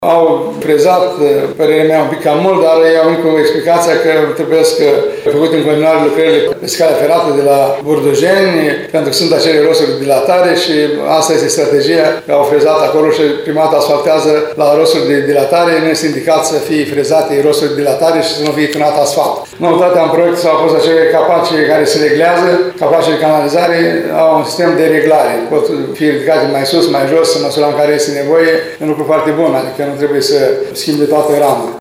El a declarat că firma SUCT  a frezat carosabilul “un pic cam mult”, dar s-a arătat mulțumit de metoda adoptată la montarea capacelor de canalizare, care se pot regla pe înălțime.